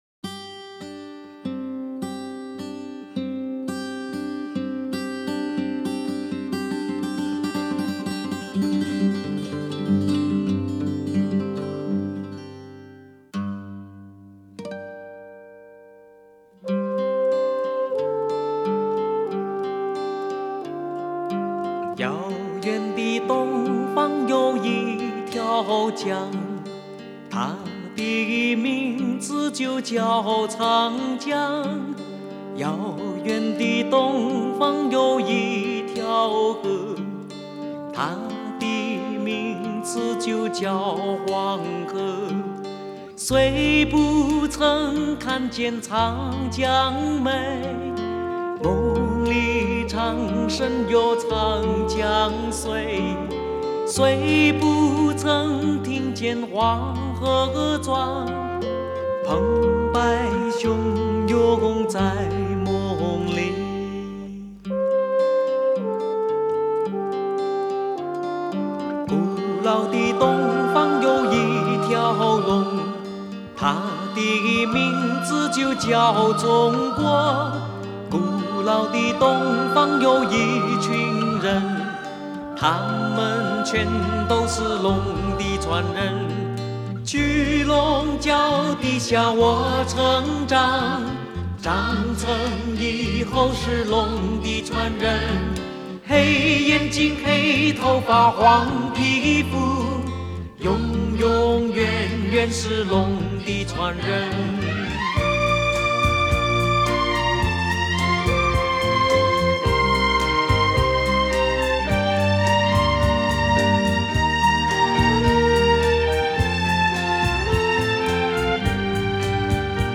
Ps：在线试听为压缩音质节选，体验无损音质请下载完整版 遥远的东方有一条江， 它的名字就叫长江。